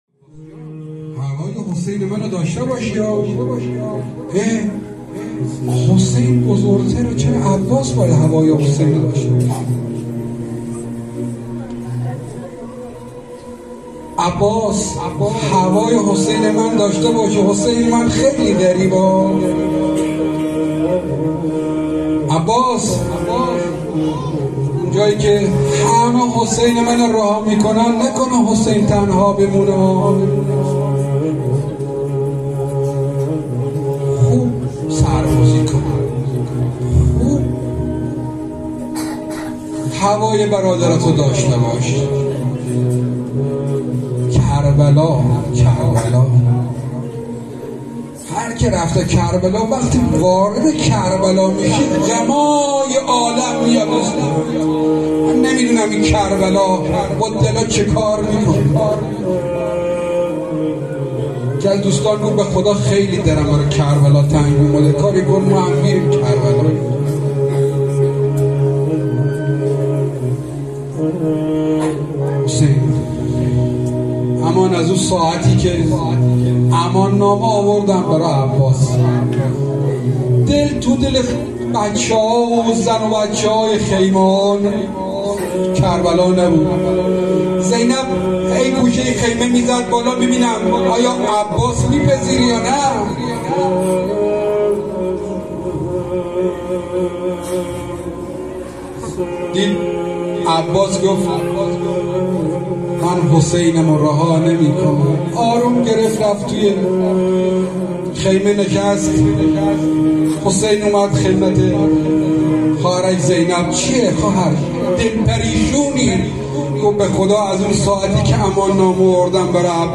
روایت گری وفات حضرت ام البنین